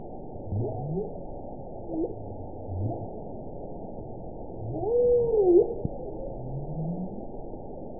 event 921916 date 12/22/24 time 19:25:25 GMT (4 months, 2 weeks ago) score 9.53 location TSS-AB04 detected by nrw target species NRW annotations +NRW Spectrogram: Frequency (kHz) vs. Time (s) audio not available .wav